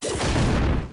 Mega Punch.mp3